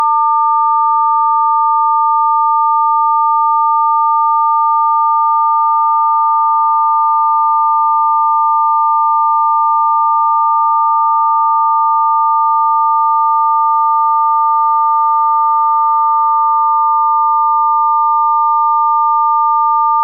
900+1100-intermod.mp3
First 10s, asymmetrical, second 10s, symmetrical
In the first 10 seconds of the 'intermod' file, the 200Hz difference tone is clearly audible, and it all but disappears when the clipping becomes symmetrical.
The sounds here have been re-recorded (again), changing the 1,200Hz tone to 1,100Hz, with a 200Hz difference frequency.
The recordings are direct from the clipping circuits.